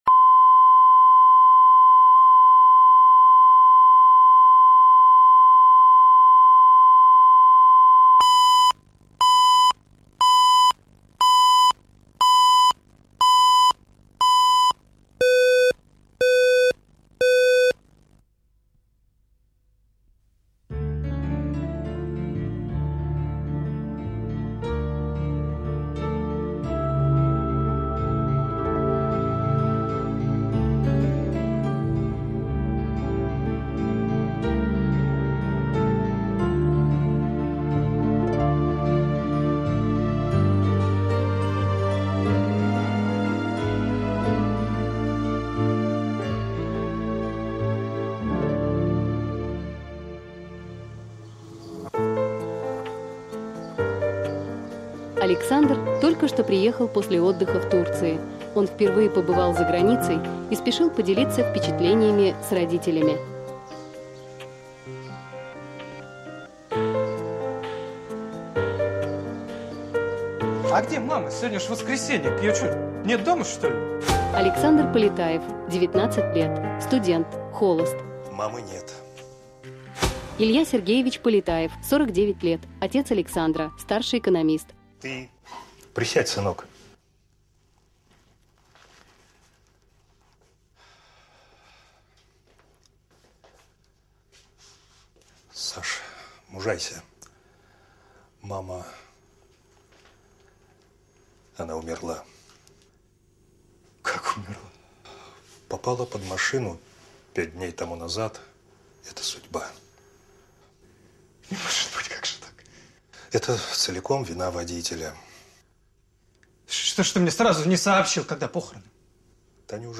Aудиокнига Мамино платье Автор Александр Левин.